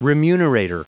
Prononciation du mot remunerator en anglais (fichier audio)
Prononciation du mot : remunerator